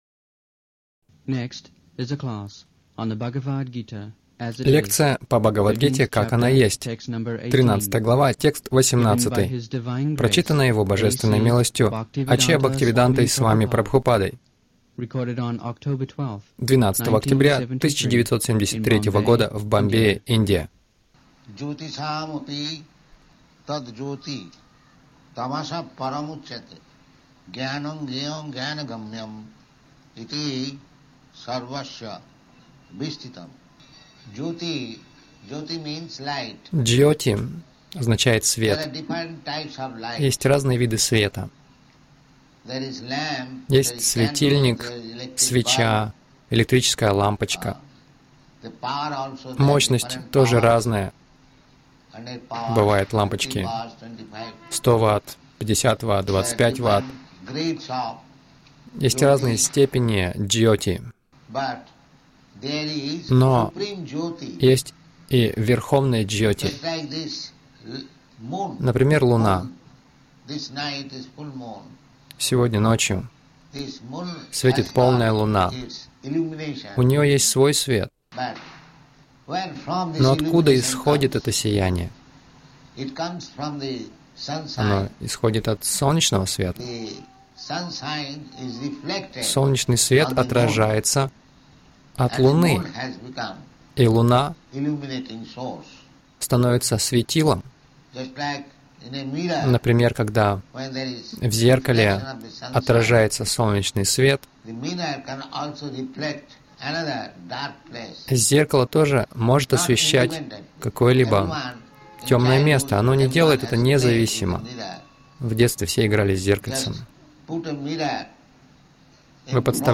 Милость Прабхупады Аудиолекции и книги 12.10.1973 Бхагавад Гита | Бомбей БГ 13.18 — Вселенское сияние Кришны Загрузка...